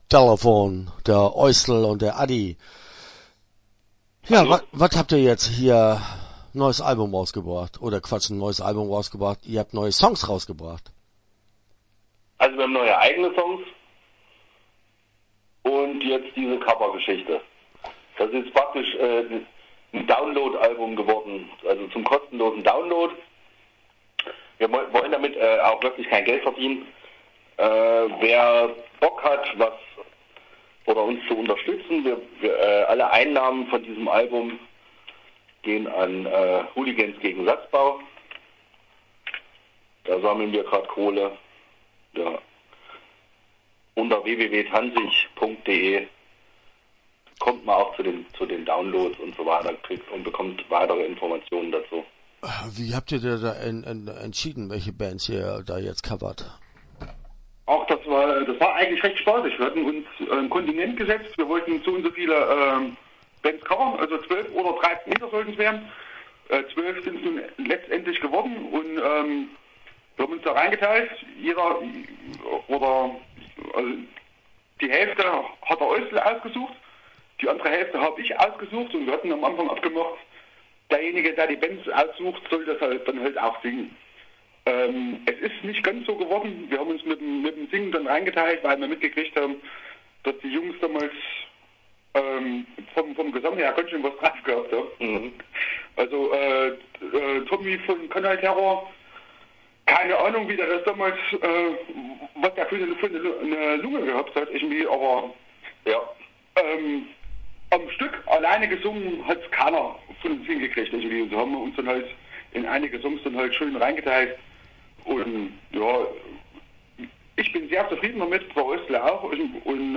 Start » Interviews » Tanzig